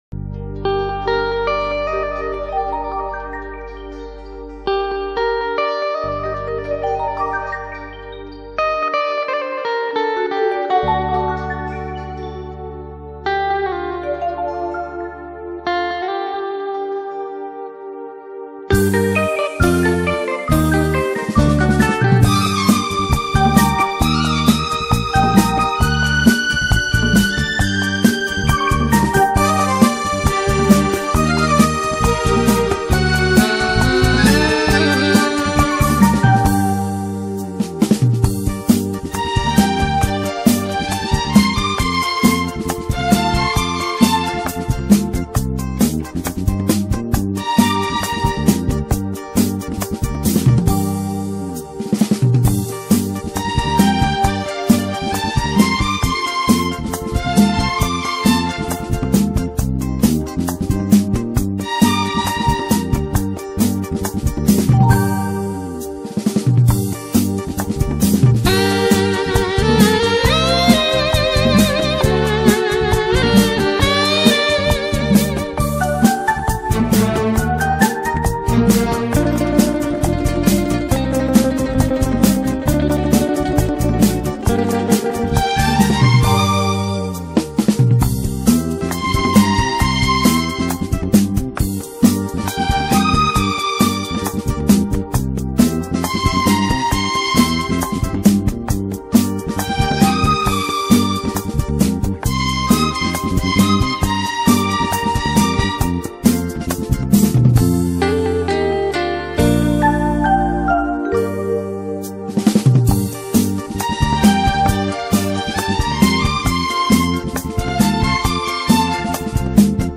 No Voice Karaoke Track Mp3 Download